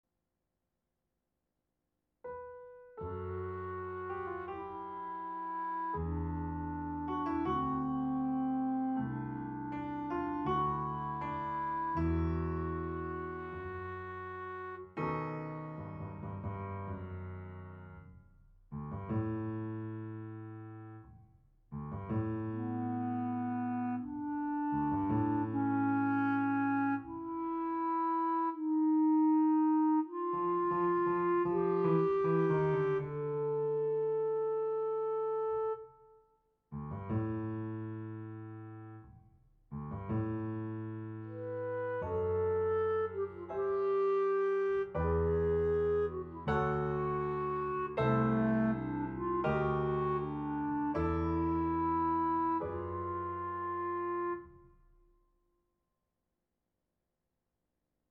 Instrumentation: Clarinet, pno